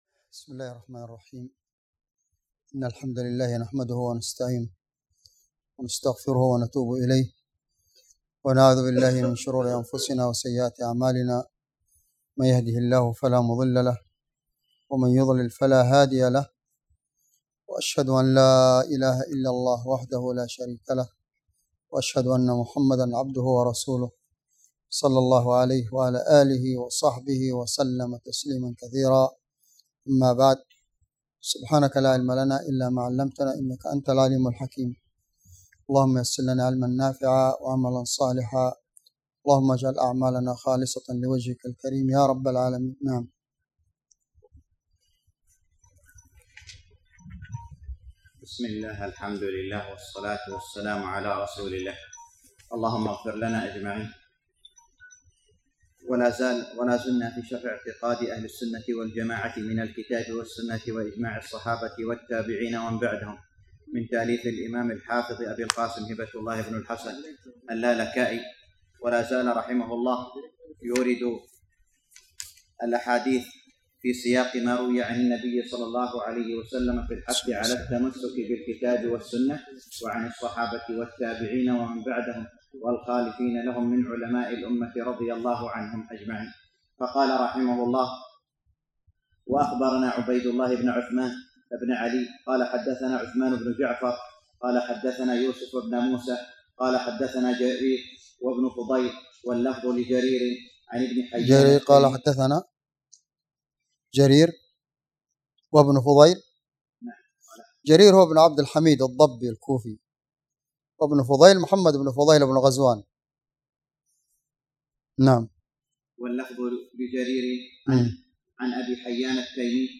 شرح أصول اعتقاد اهل السنة والجماعة الامام الحافظ اللالكائي والمقام بجامع الخير في ابوعريش